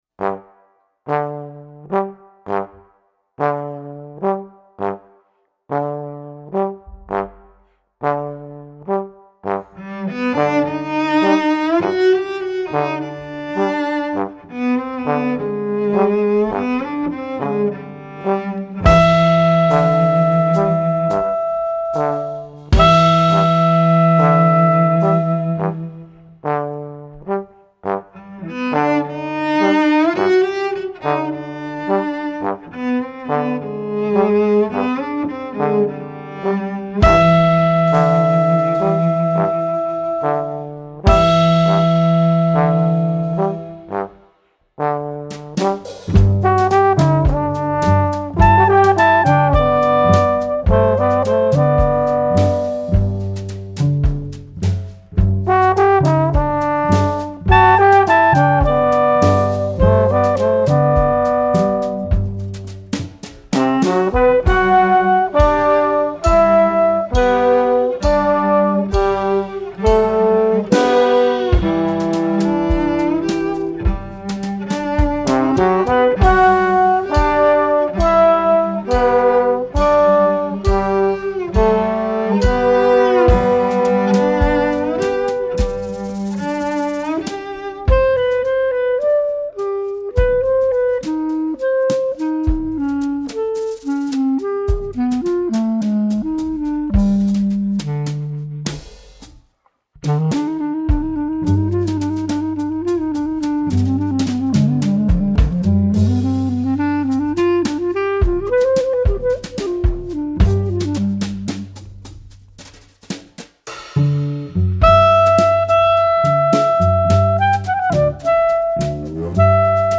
Drums
Flute/Clarinet